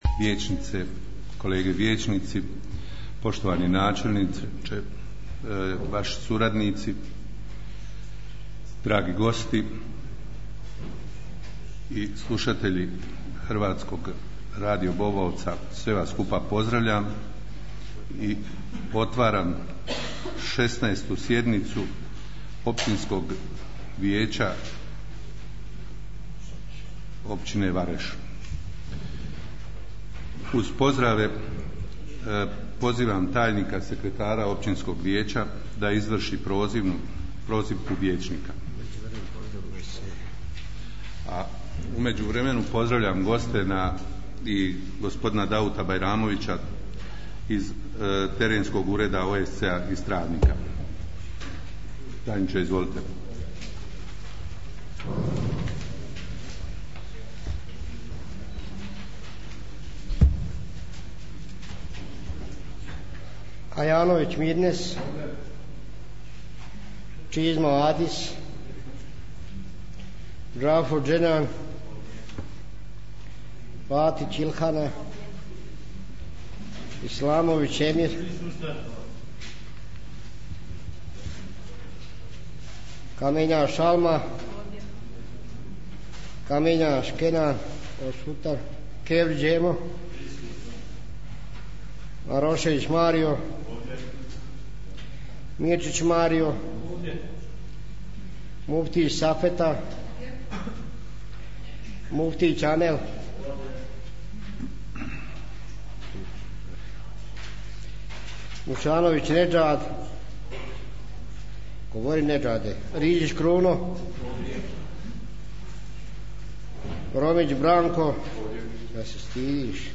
16. sjednica Općinskog vijeća - tonski snimak
28.03.2018. godine održana je 16. sjednica Općinskog vijeća, poslušajte tonski zapis...